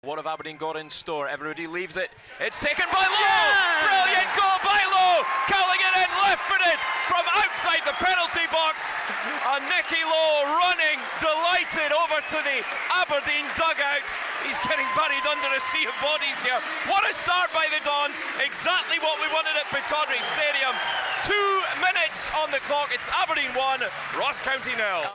Aberdeen 1-0 Ross County. Red TV goal commentary.